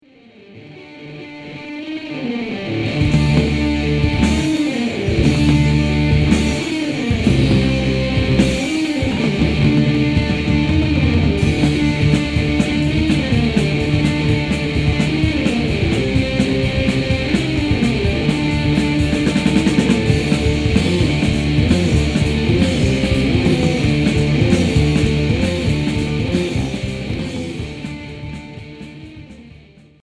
Hard heavy Unique 45t retour à l'accueil